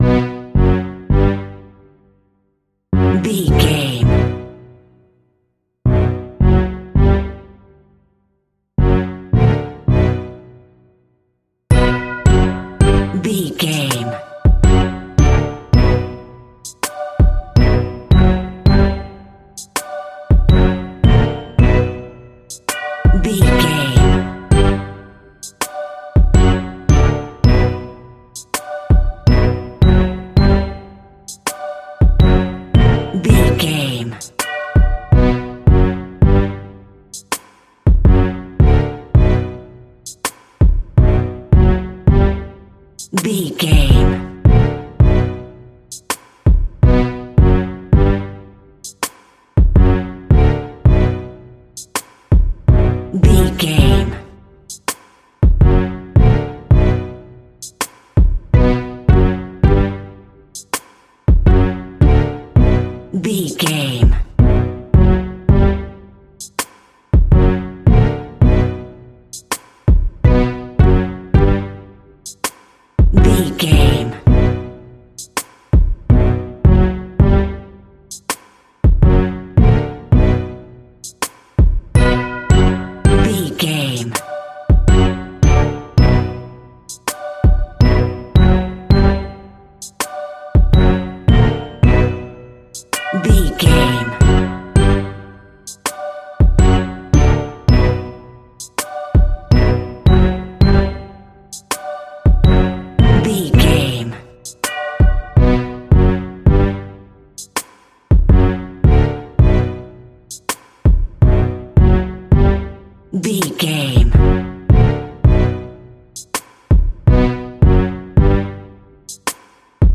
Scary Rap Music Cue.
Aeolian/Minor
Slow
hip hop
chilled
laid back
Deep
hip hop drums
hip hop synths
piano
hip hop pads